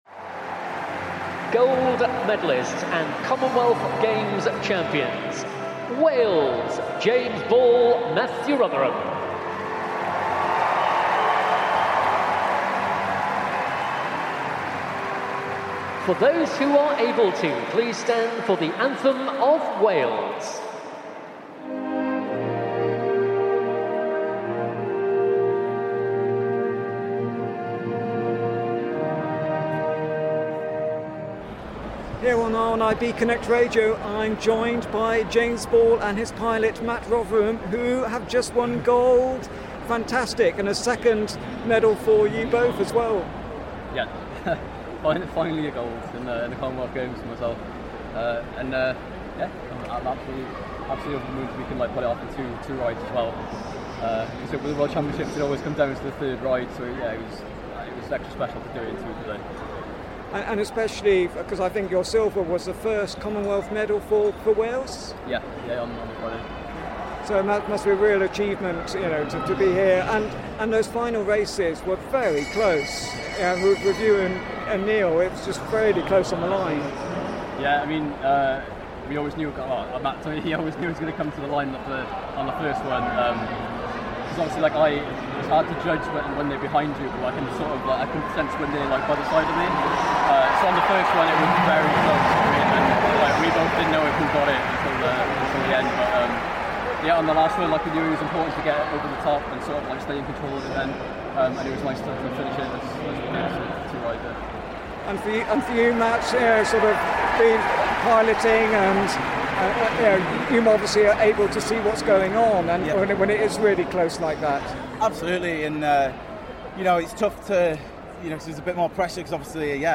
More from the Lee Valley Velodrome